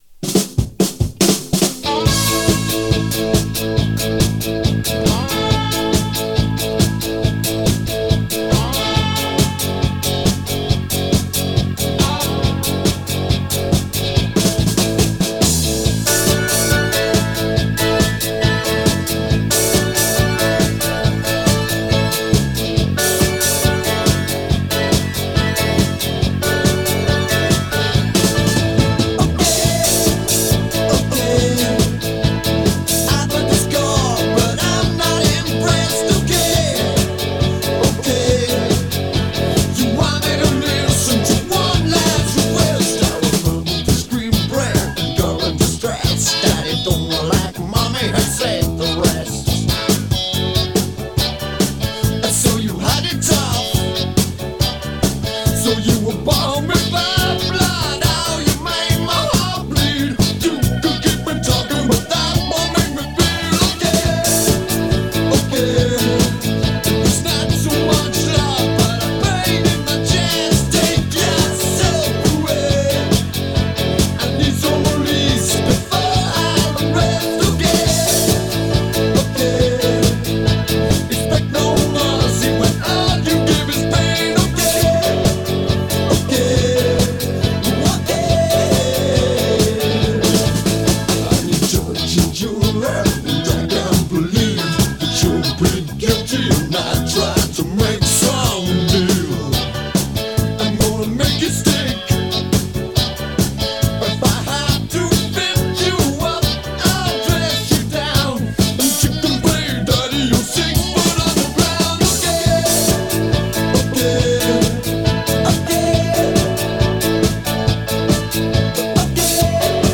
Category: New Wave, Song of the Day